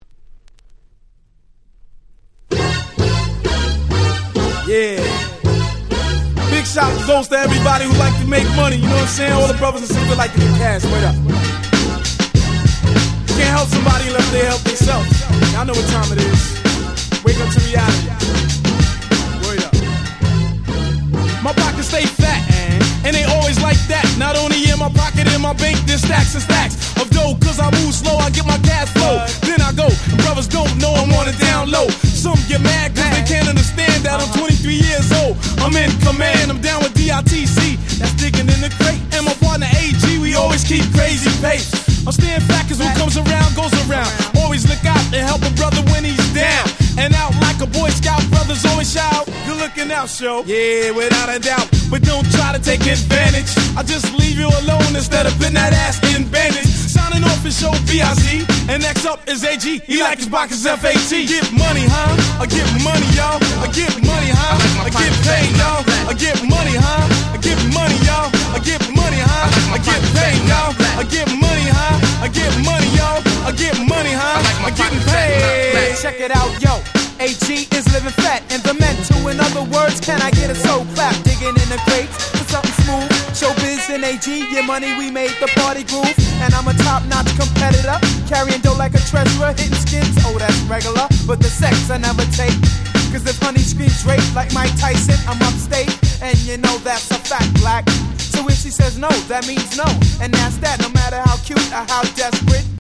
92' Super Hip Hop Classics !!